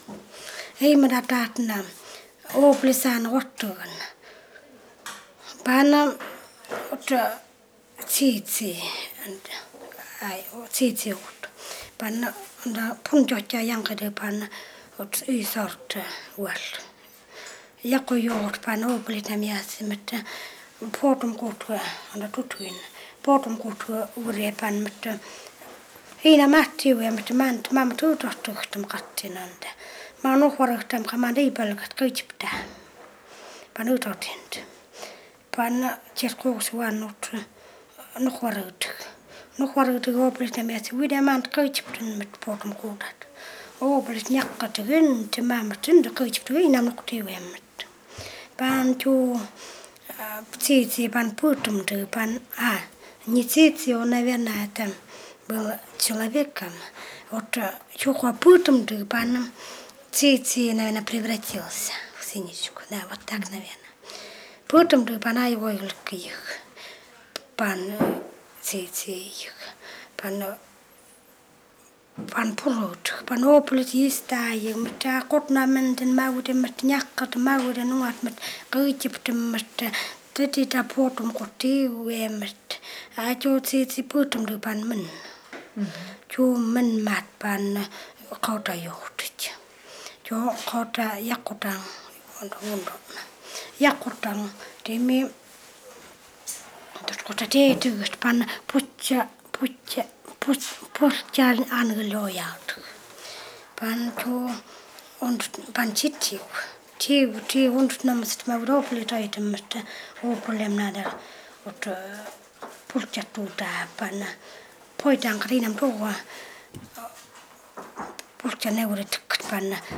yugan khanty (YK)
Tales (tal)